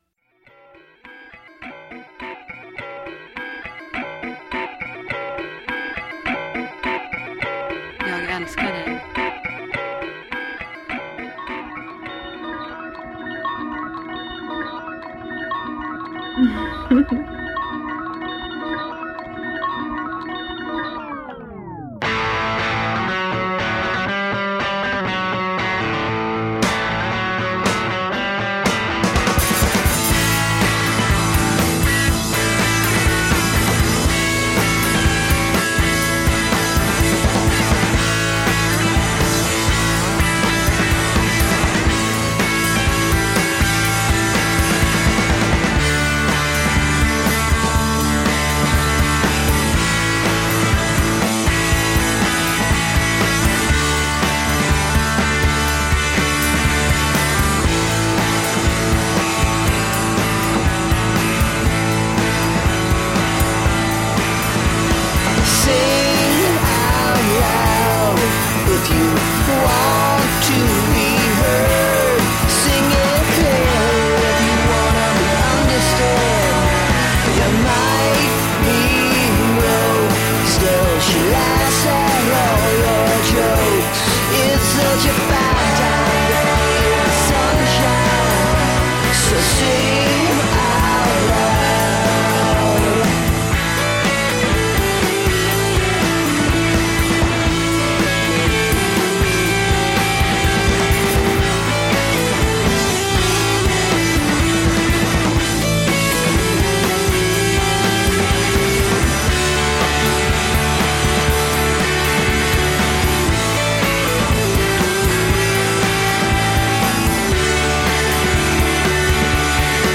with a dusting of noise and two heaping cups of pop.